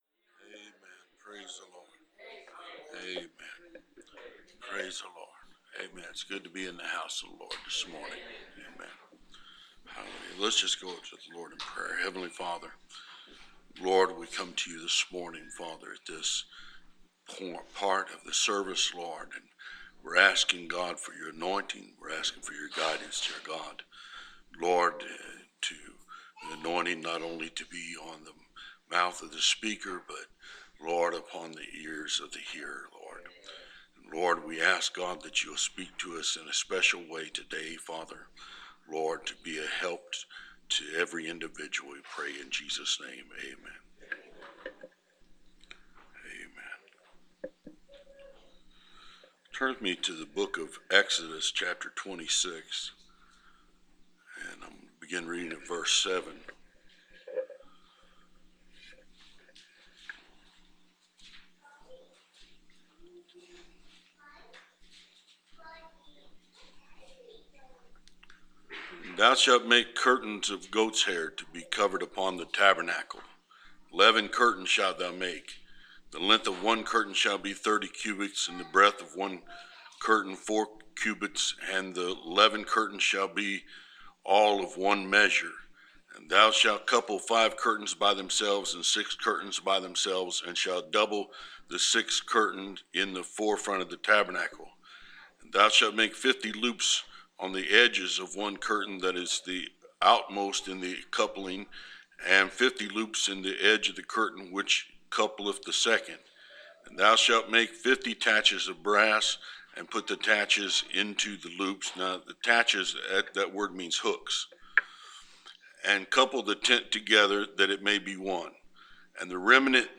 Preached